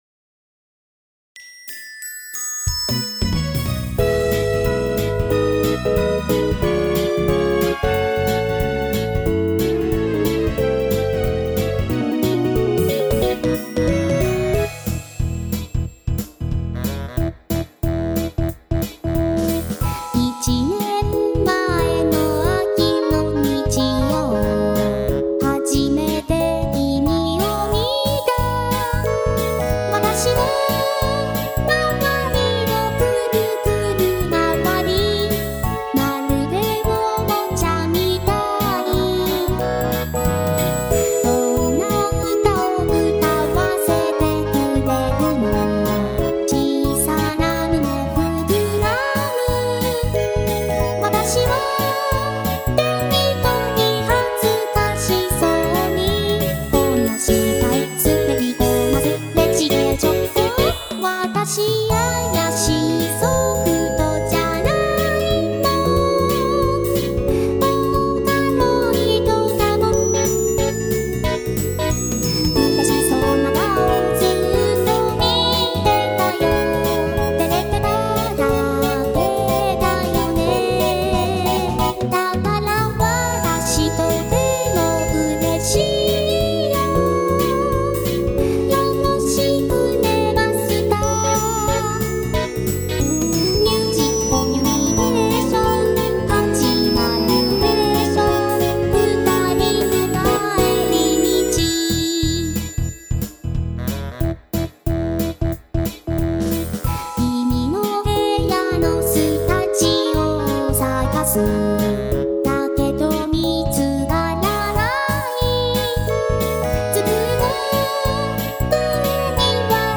歌うソフトウェア音源「VOCALOID」を使った企画もの。